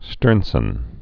(stûrnsən)